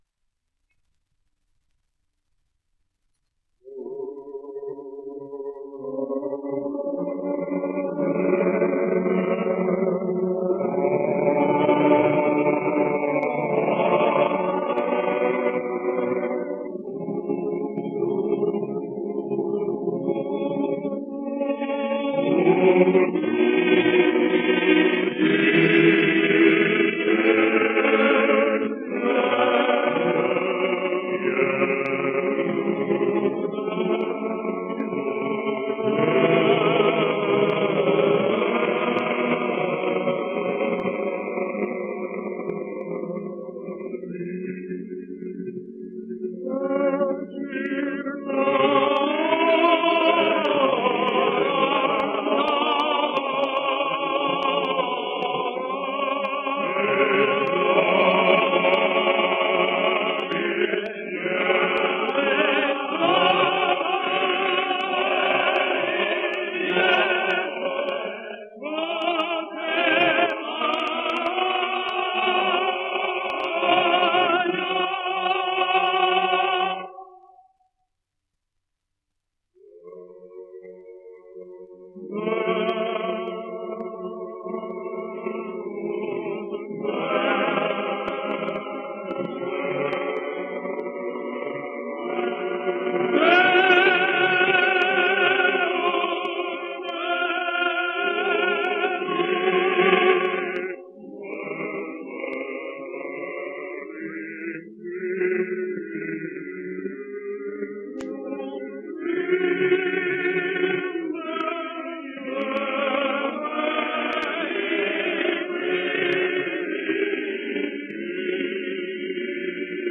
Tenebrae factae sunt (sonido remasterizado)